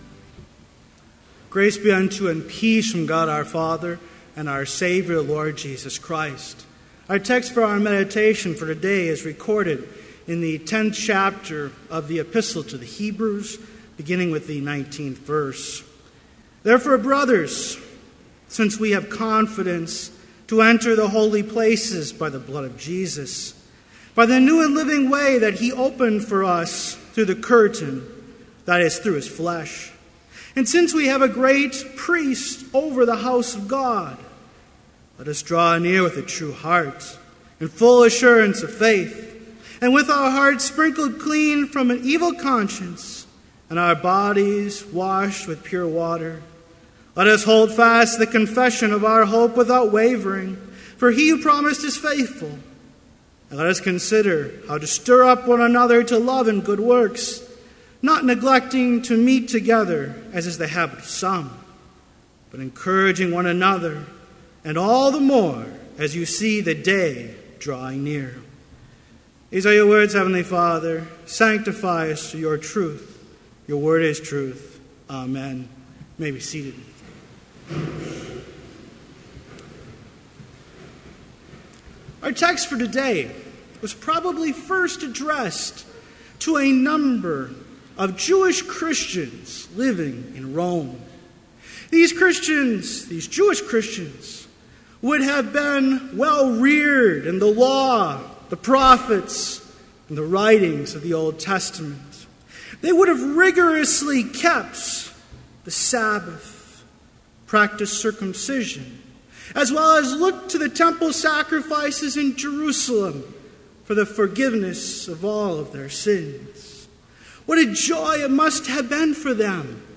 Complete service audio for Chapel - October 20, 2014